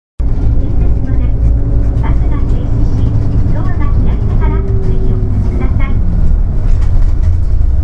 音声合成装置  クラリオン(ディスプレイ１)